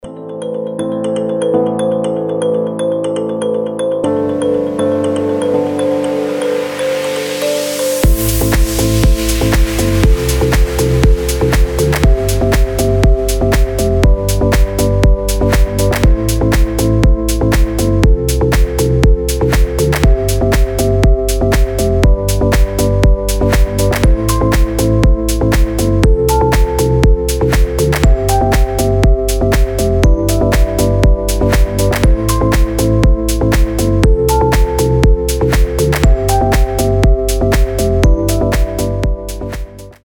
красивые
deep house
мелодичные
Electronic
без слов
космические
колокольчики
Красивая мелодия на звонок или будильник